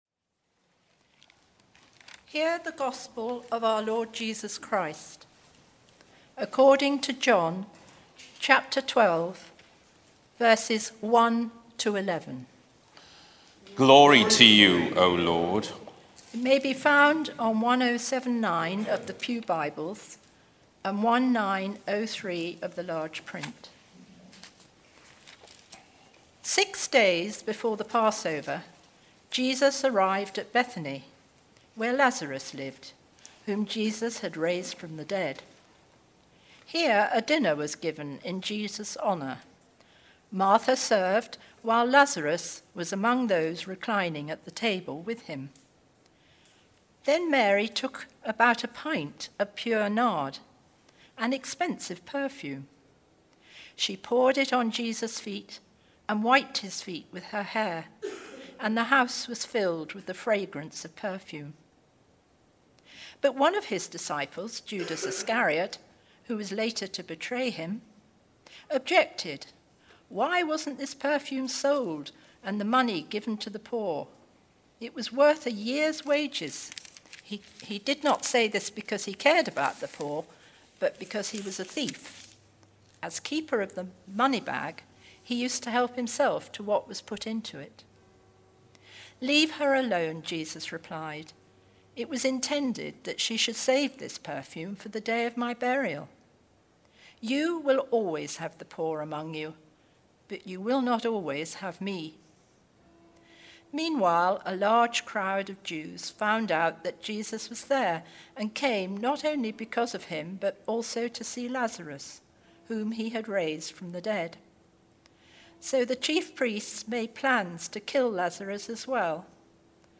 St Mary’s Church Wargrave
Passage: John 12:1-8, Series: Lent Theme: Perfume Sermon